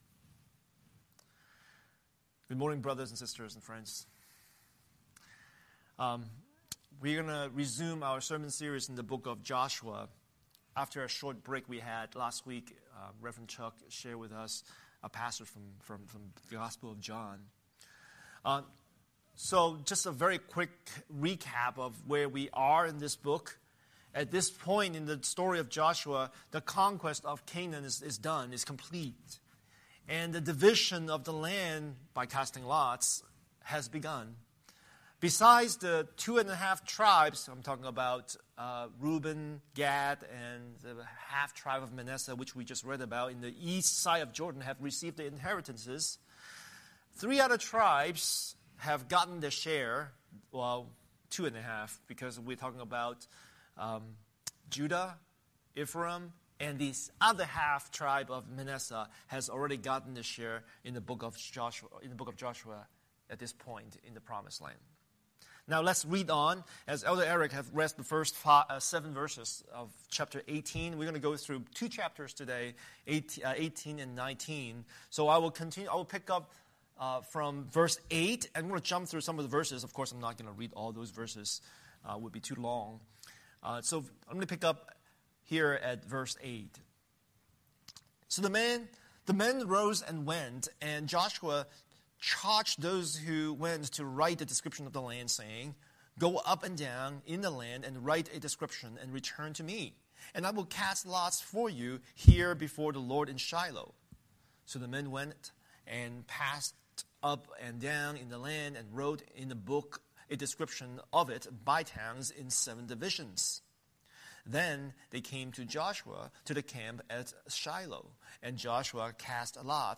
Scripture: Joshua 18:1–19:51 Series: Sunday Sermon